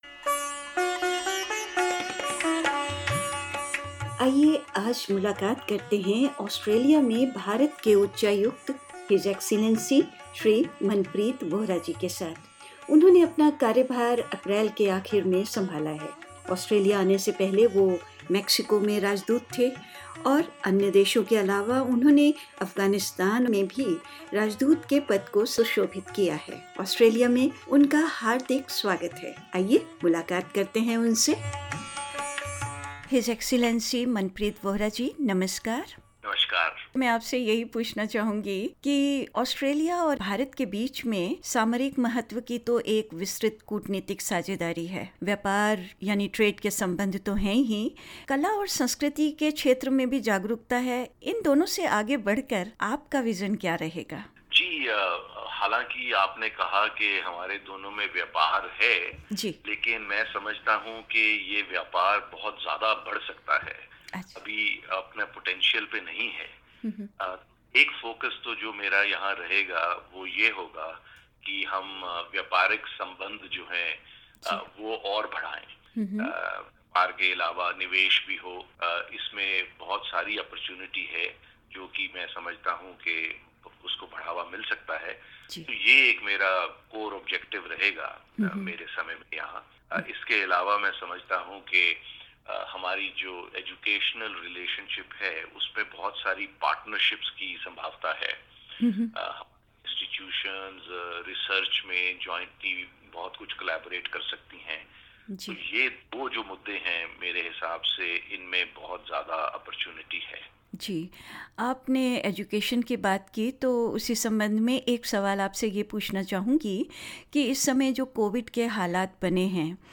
I am making efforts so that the Australian government resolves this issue at the earliest," Mr Vohra told SBS Hindi in an exclusive interview.